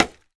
Minecraft Version Minecraft Version 1.21.5 Latest Release | Latest Snapshot 1.21.5 / assets / minecraft / sounds / block / scaffold / place3.ogg Compare With Compare With Latest Release | Latest Snapshot